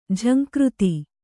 ♪ jhaŋkřti